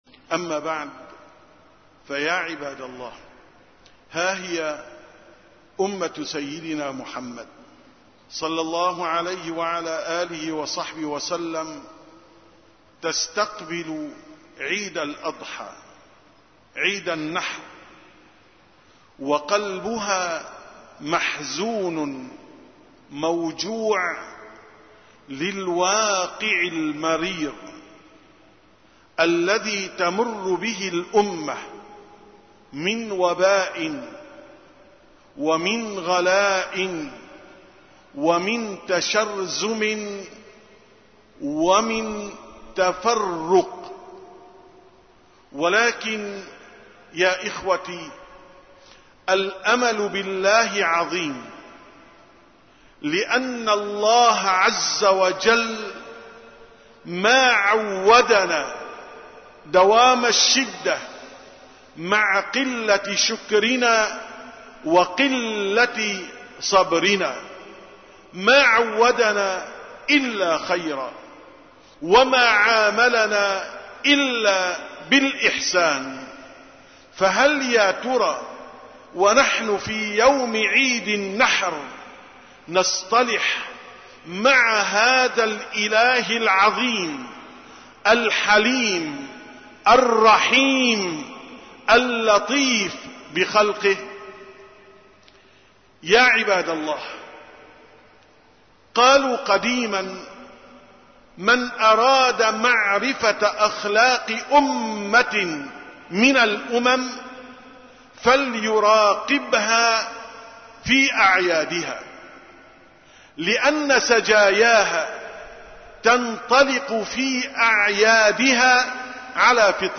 718ـ خطبة العيد: العيد في الإسلام